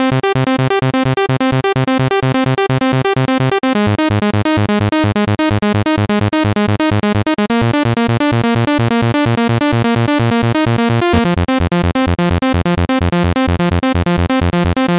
To do this I soloed the first synth using the mixer and played in a pretty standard trance type riff.
You can hear how basic this sounds with one saw, not particularly interesting at all.
The raw, single saw wave and basic pattern played back.